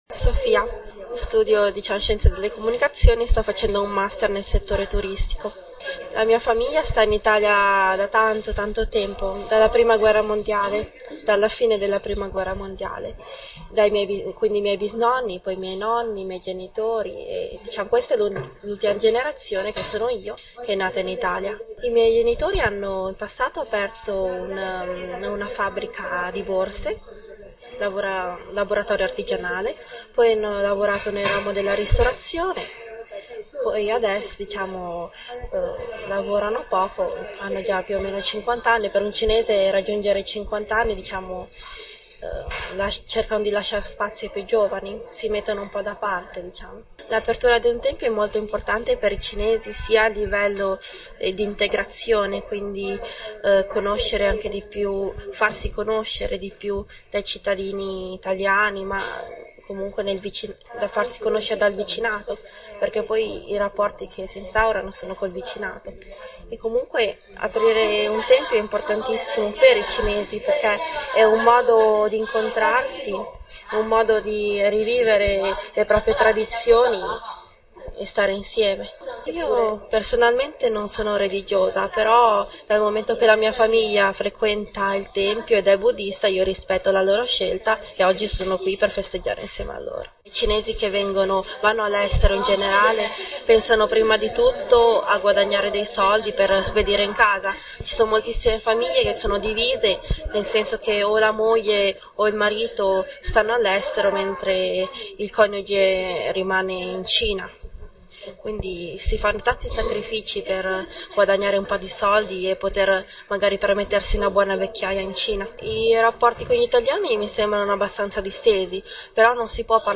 Interviste audio